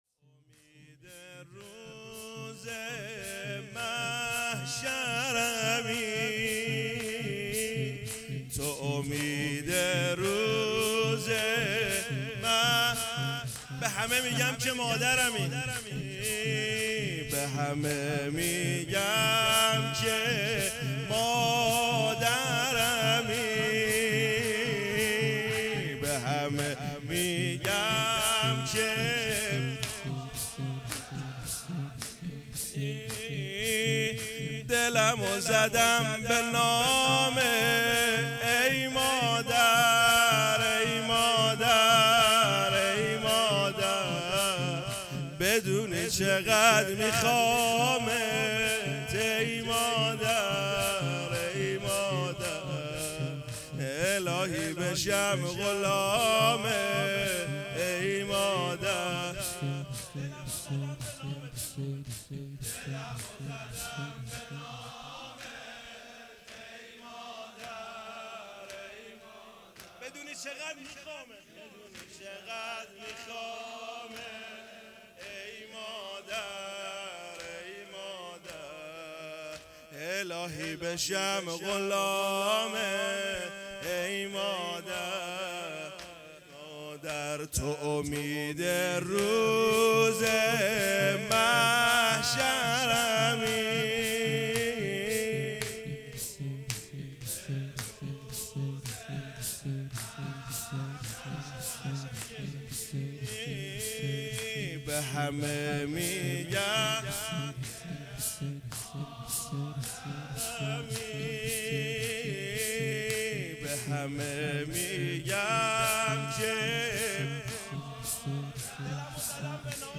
جلسه هفتگی و مراسم یادبود رحلت آیت الله مصباح یزدی ۱۸ دی ۹۹